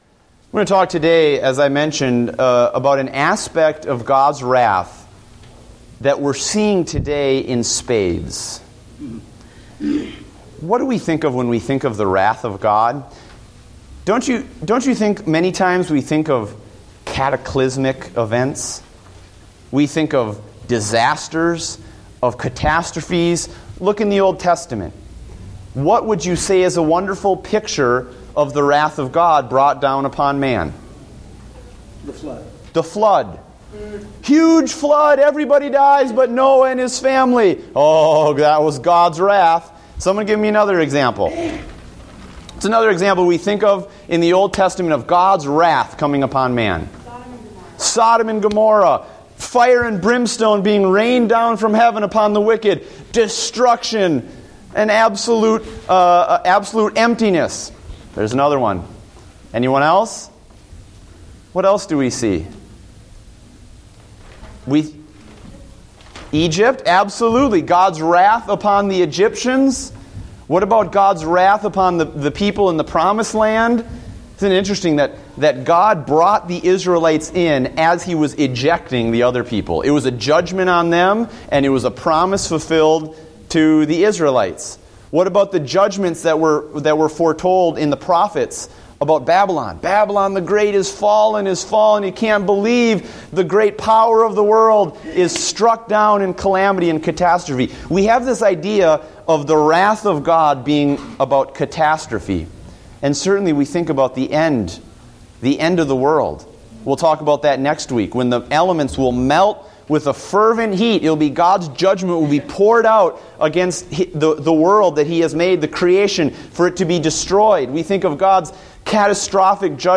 Date: July 13, 2014 (Adult Sunday School)